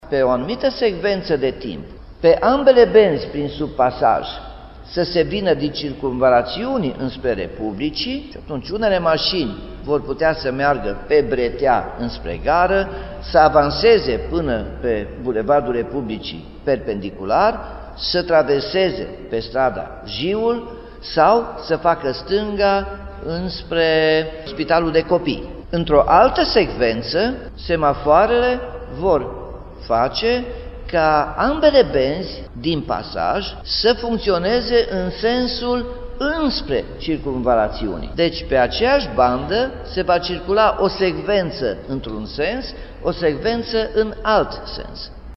Pe același principiu, șoferii vor putea circula și spre Circumvalațiunii, totul fiind coordonat de semafoare, precizează Nicolae Robu: